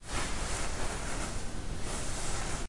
Magic Fur: Sound effect
swirls_fur.ogg